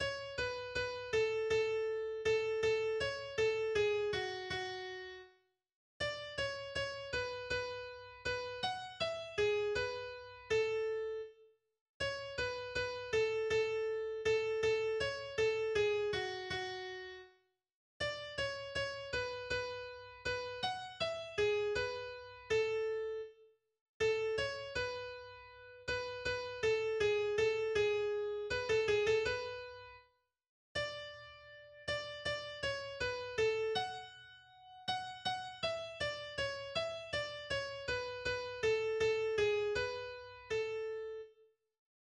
Volkslied